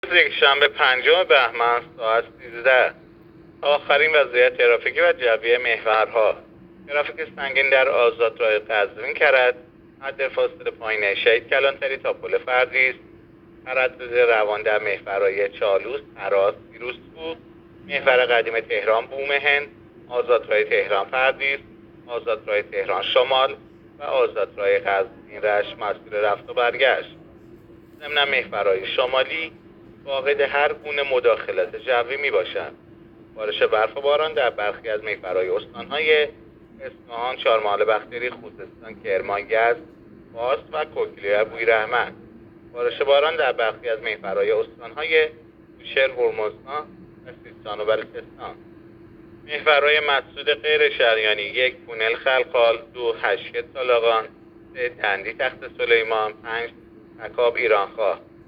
گزارش رادیو اینترنتی از آخرین وضعیت ترافیکی جاده‌ها ساعت ۱۳ پنجم بهمن؛